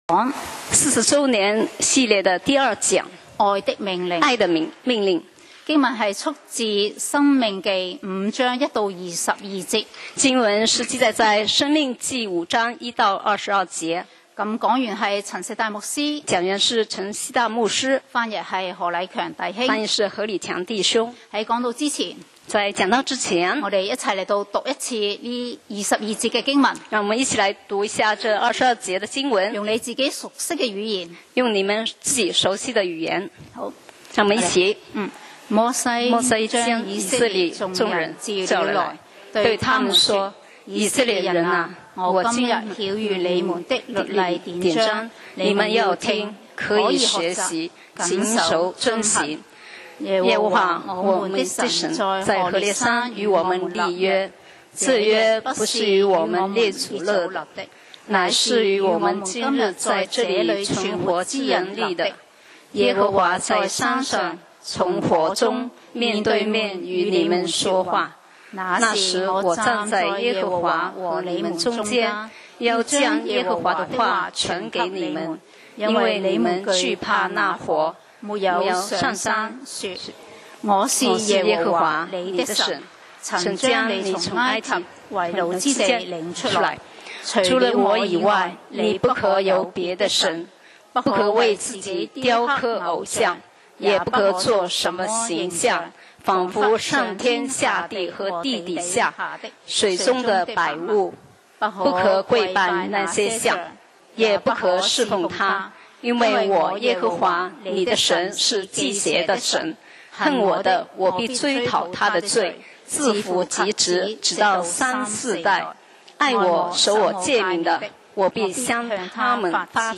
講道 Sermon 題目 Topic：爱的命令-40周年系列二 經文 Verses：申5：1-22. 1摩西将以色列众人召了来，对他们说，以色列人哪，我今日晓谕你们的律例典章，你们要听，可以学习，谨守遵行。